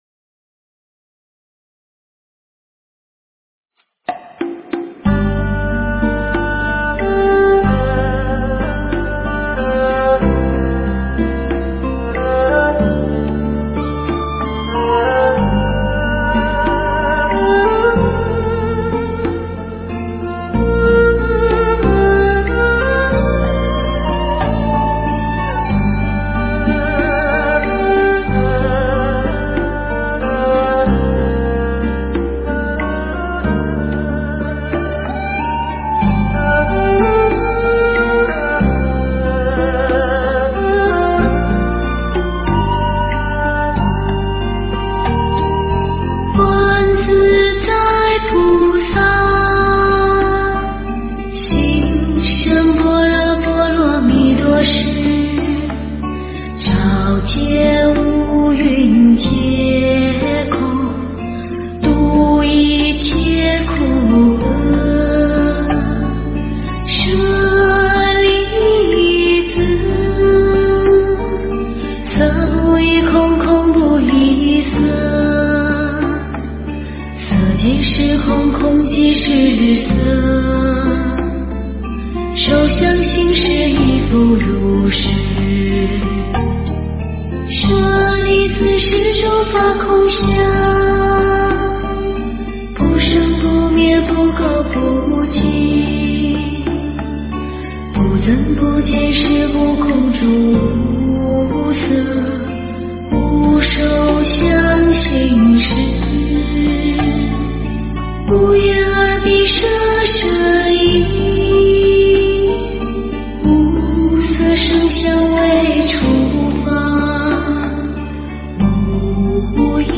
佛音 诵经 佛教音乐